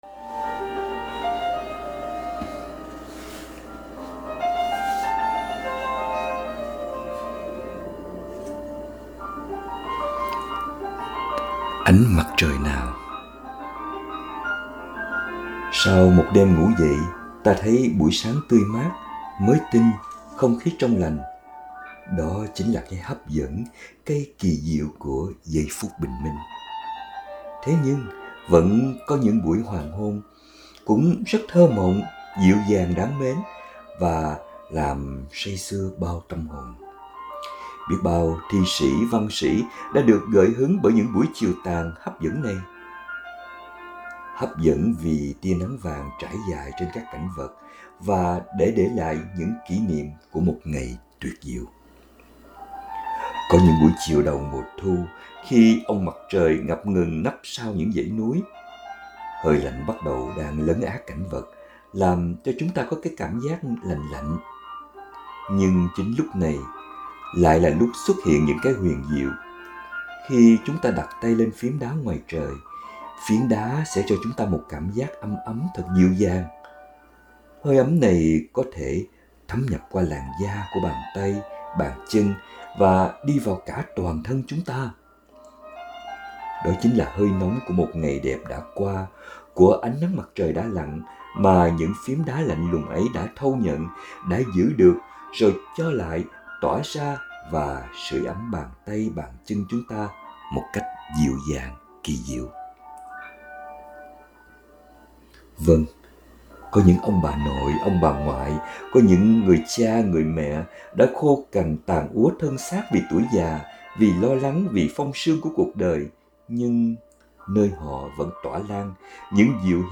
2025 Audio Suy Niệm https